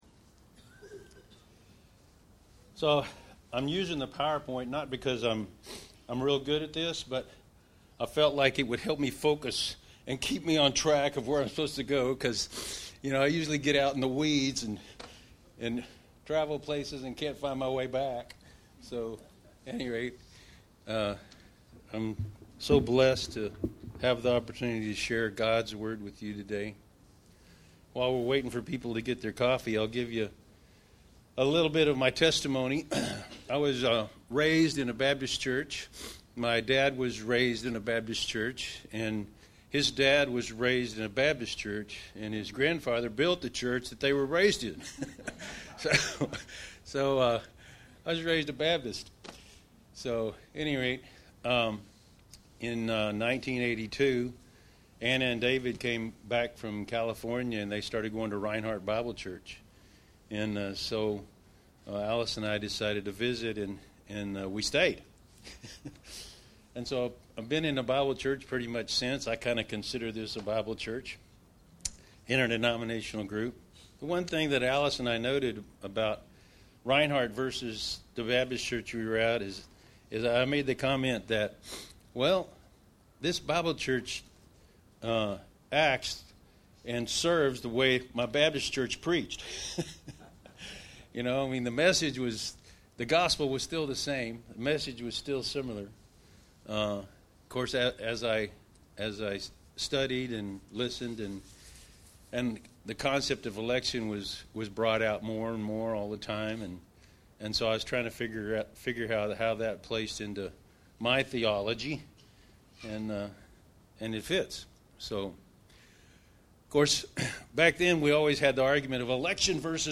Passage: Genesis 1:26-28, Romans 8:27-30, 2 Thessalonians 2:13-15 Service Type: Sunday Morning « Spiritual Gifts